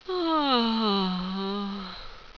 Index of /tactics/sfx/pain/sultry
aaahhh.wav